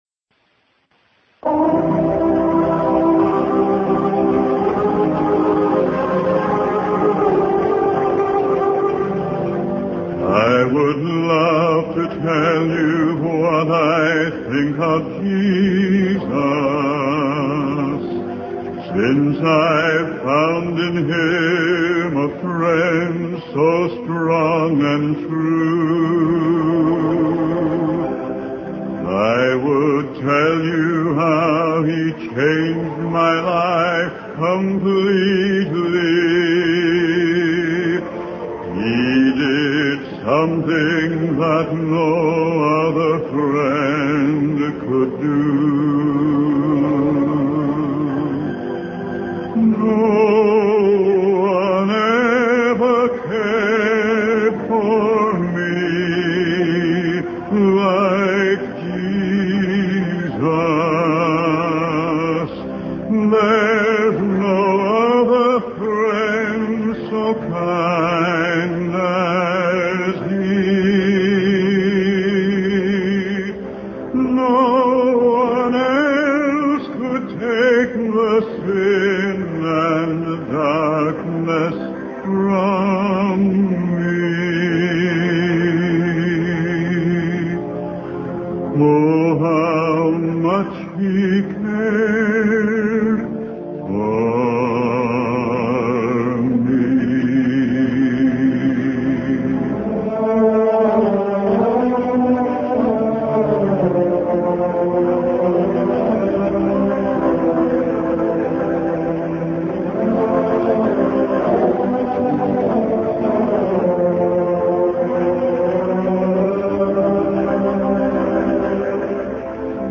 You're listening to Lesson 1 from the sermon series "Standing